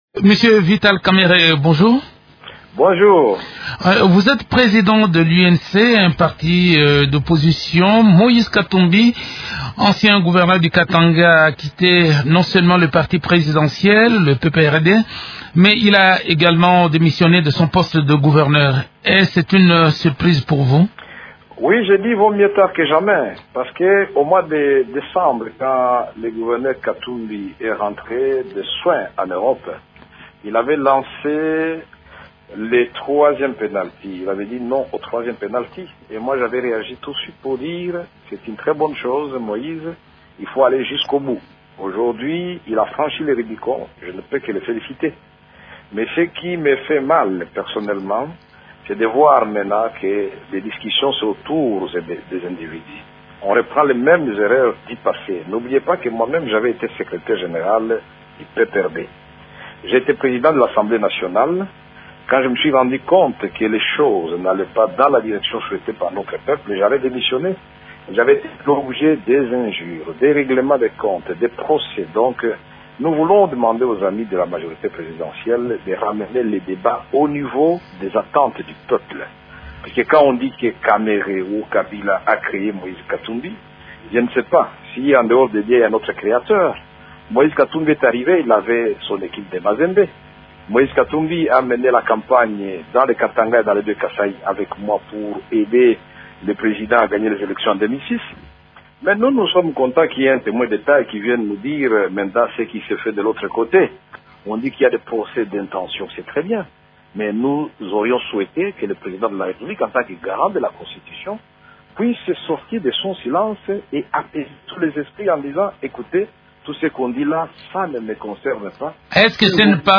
Le président du parti de l’opposition Union pour la nation congolaise (UNC), Vital Kamerhe, est l’invité de Radio Okapi ce lundi.